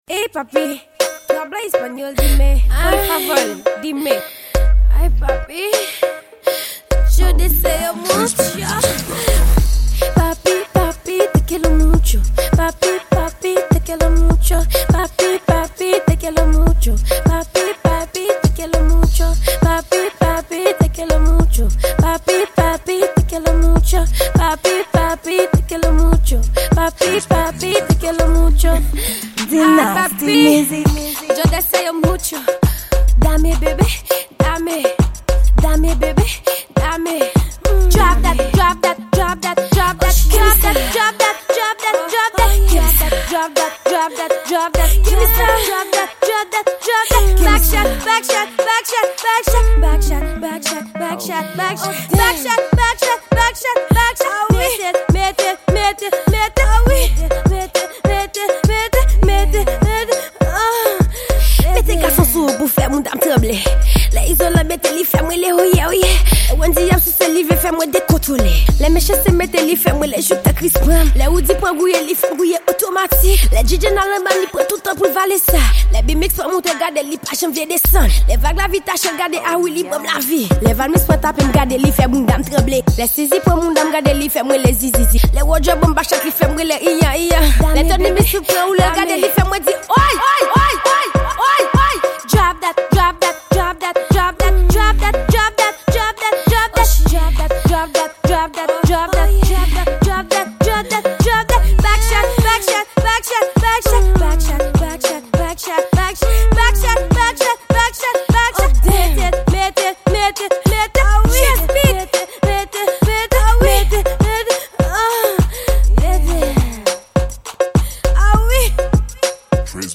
Genre: Raggaeton.